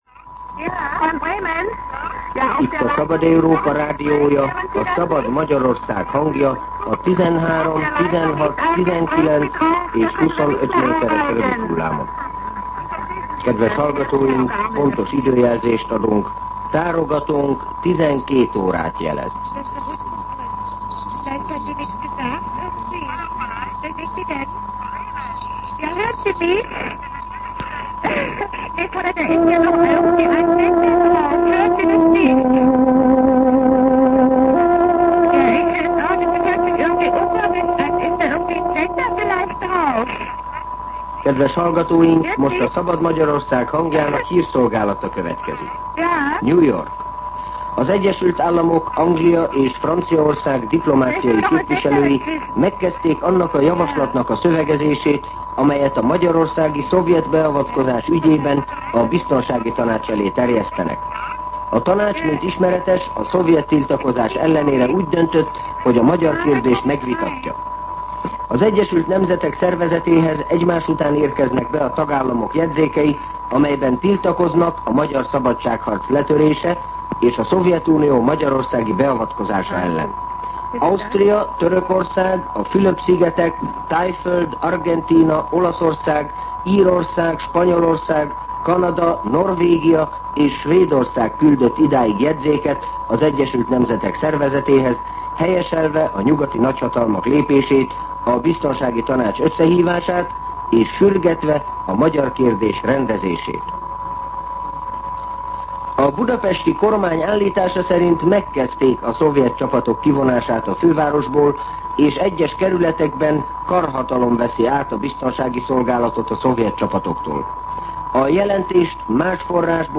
12:00 óra. Hírszolgálat